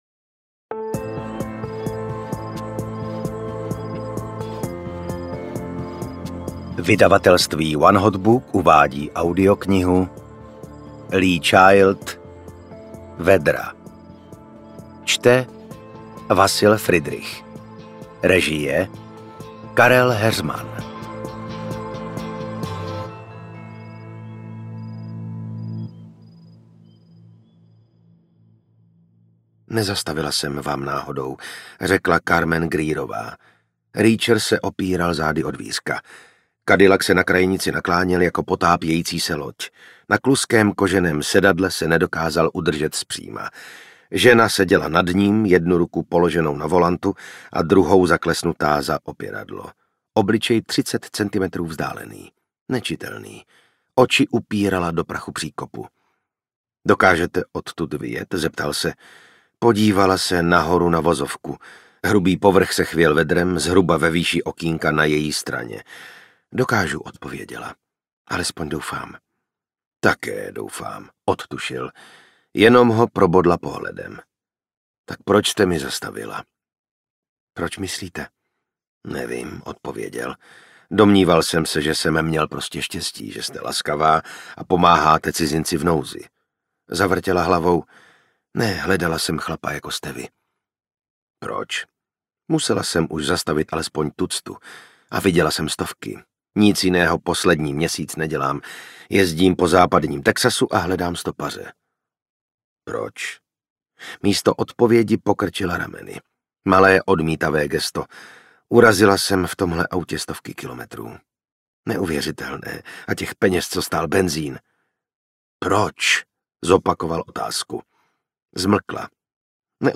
Jack Reacher: Vedra audiokniha
Ukázka z knihy